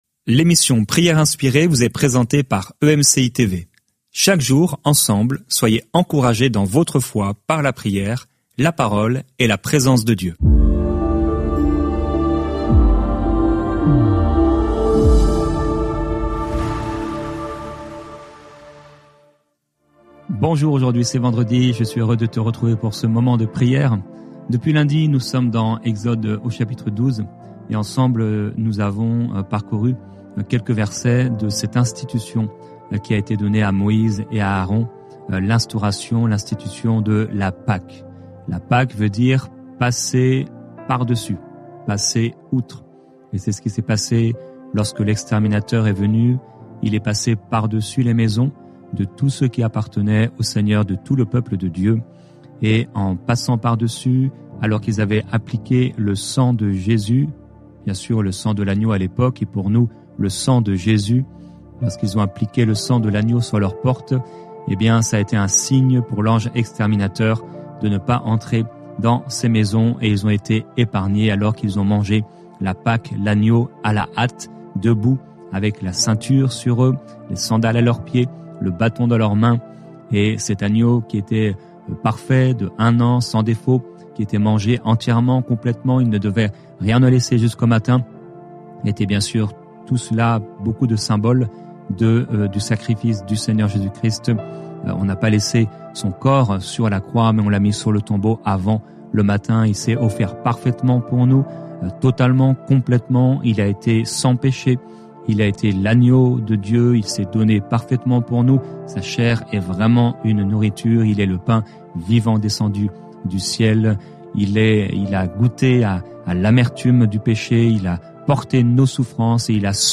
Au programme, une pensée du jour, un temps de louange, l'encouragement du jour et un temps de prière et de déclaration prop